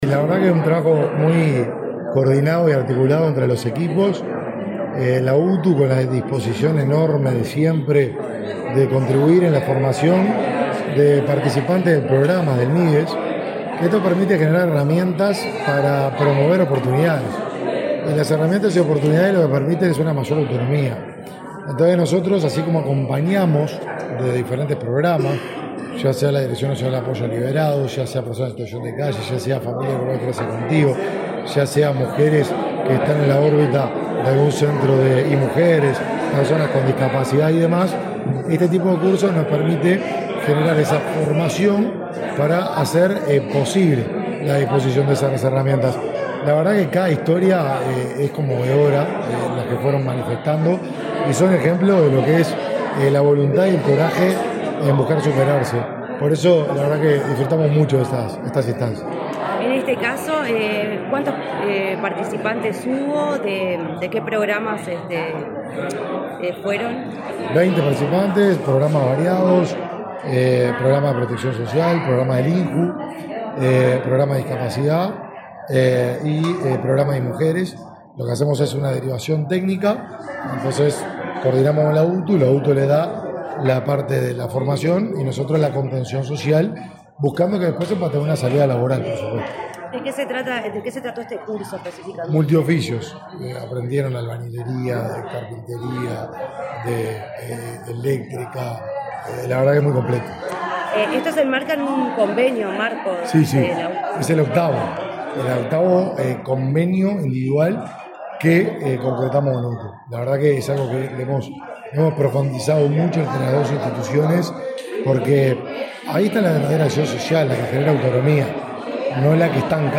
Entrevista al ministro de Desarrollo Social, Martín Lema
Este jueves 14 en Montevideo, el ministro de Desarrollo Social, Martín Lema, dialogó con Comunicación Presidencial, luego de participar de la entrega